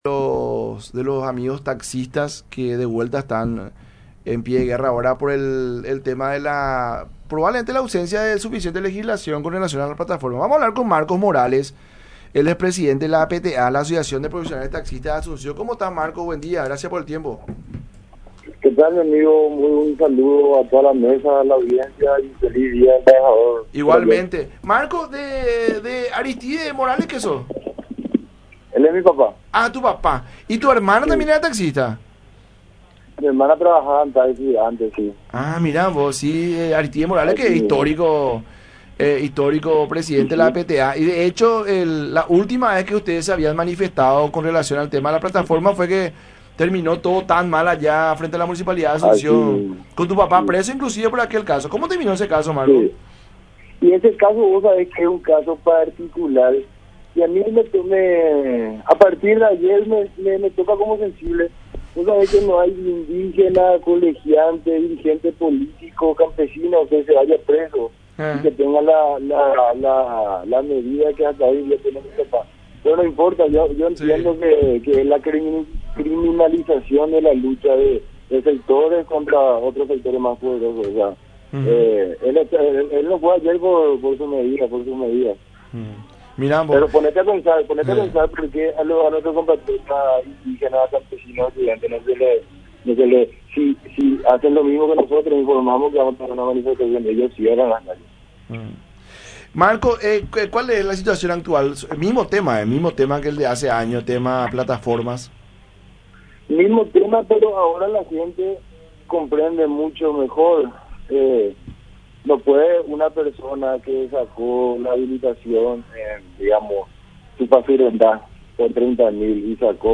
Nosotros somos hombres de derechos y hay que respetar las instituciones”, agregó en el programa “La Gran Mañana De Unión” por radio La Unión y Unión Tv.